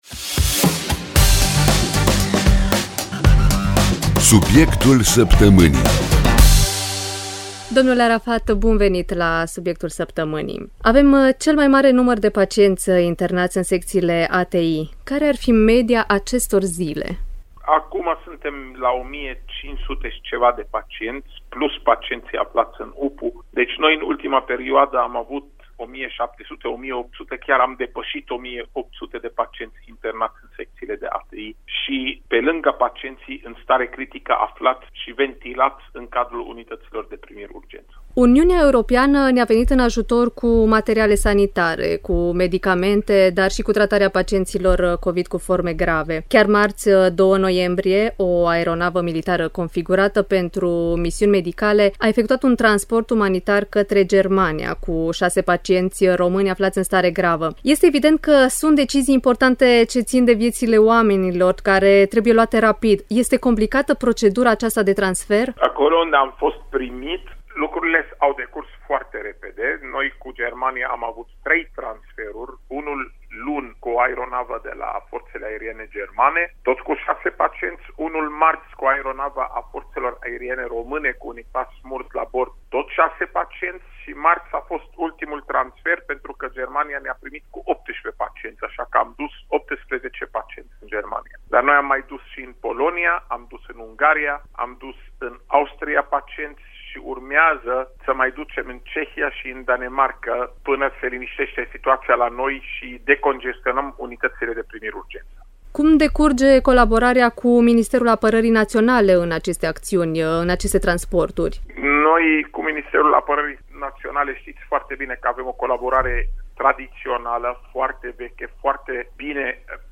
Invitat la rubrica săptămânală “Subiectul Săptămânii” a fost Raed Arafat, Secretar de stat în Ministerul Afacerilor Interne. În această perioadă sunt transportați în Uniunea Europeană o serie de pacienți de la București cu un avion de transport C-27 Spartan.